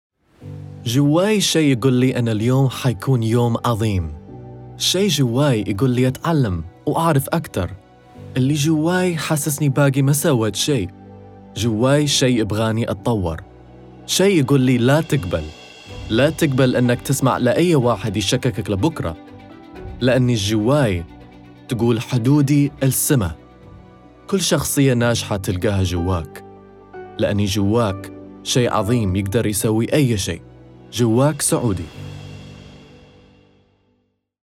Commercial Reel (Saudi Arabic)
Conversational, Bright, Natural